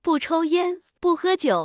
tts_result_11.wav